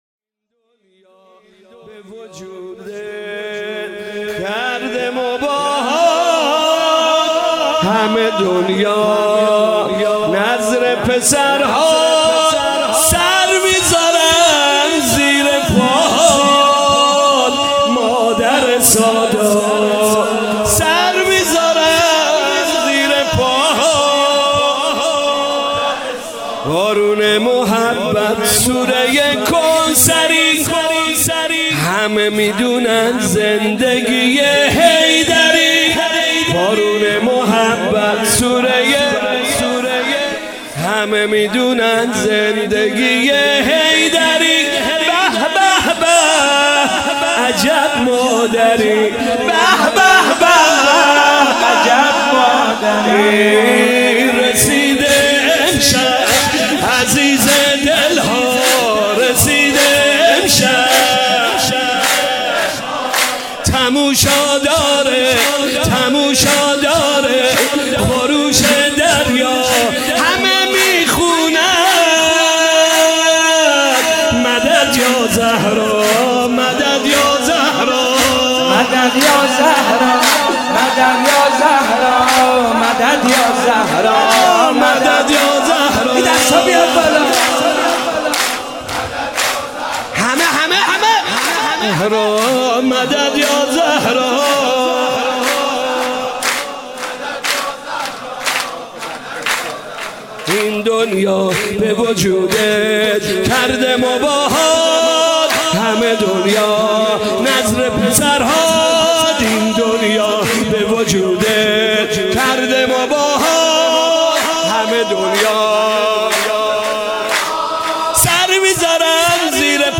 ولادت حضرت زهراسلام الله علیها